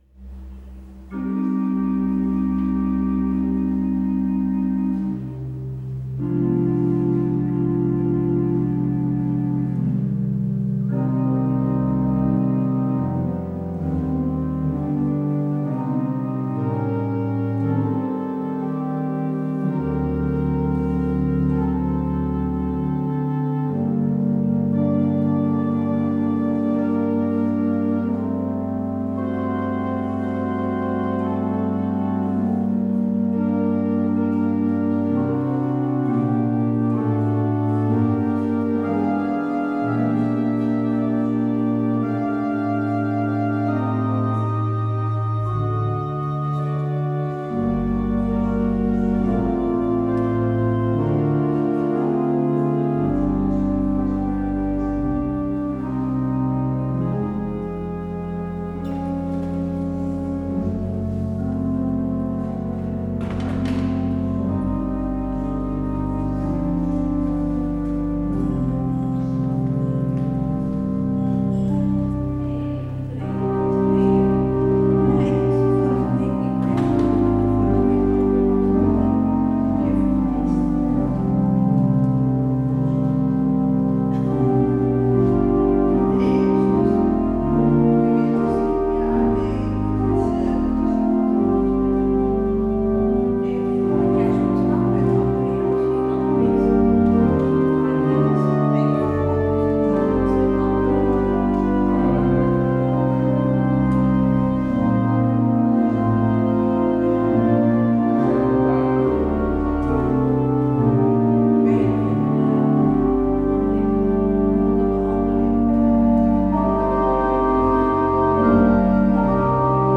 Beluister deze kerkdienst hier: Alle-Dag-Kerk 11 februari 2026 Alle-Dag-Kerk https